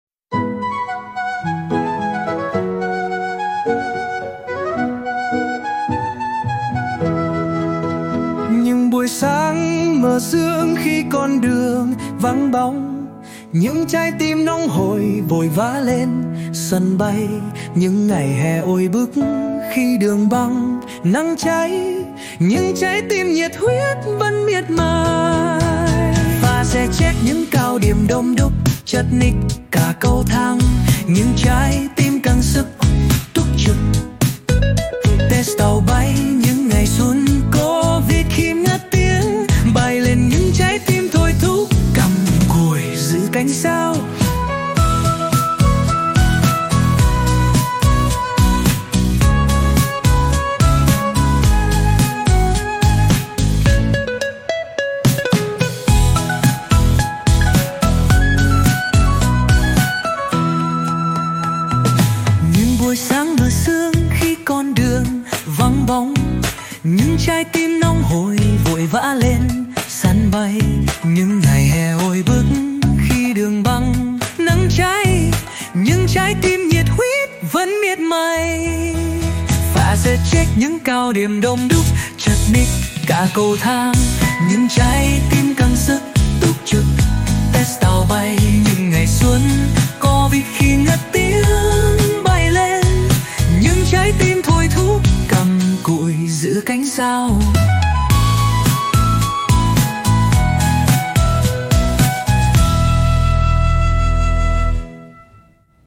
giai điệu sâu lắng